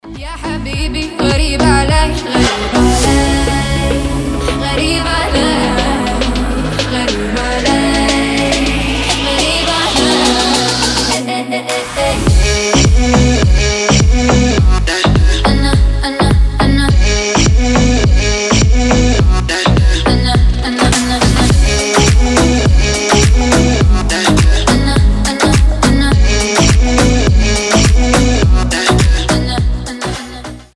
• Качество: 320, Stereo
поп
арабские